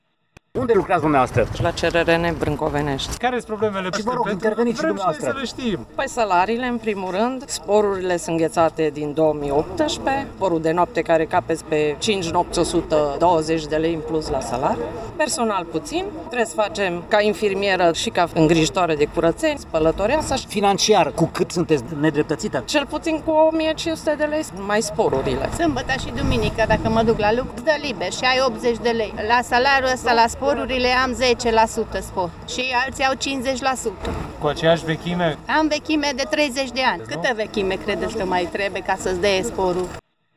Nemulțumiți de aceste condiții, membri ai sindicatului Acord au protestat, astăzi, în fața Palatului Administrativ din Târgu Mureș.
Angajații din sistemul de protecție socială reclamă salarii cu 50% mai mici iar sporurile lor nu au mai crescut de 6 ani: